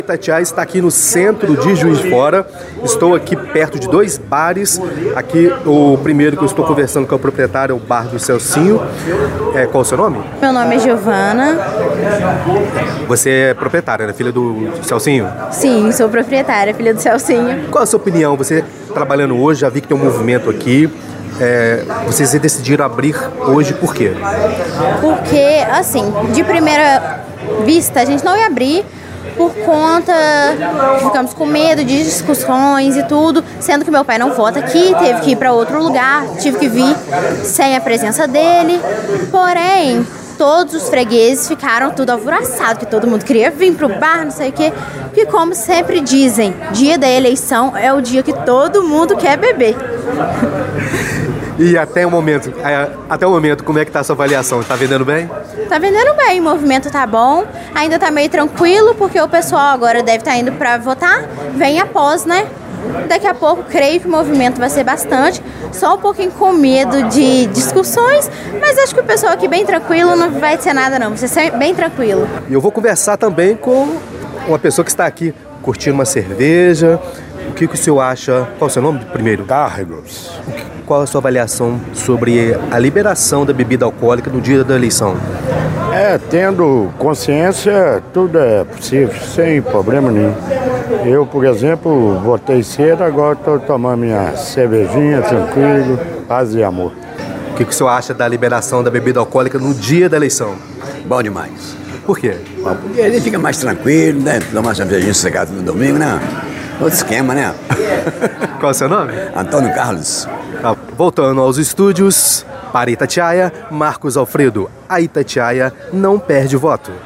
A reportagem da Itatiaia passou em alguns estabelecimentos no centro de Juiz de Fora para saber a opinião das pessoas sobre essa questão.